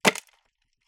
ice fall 21.wav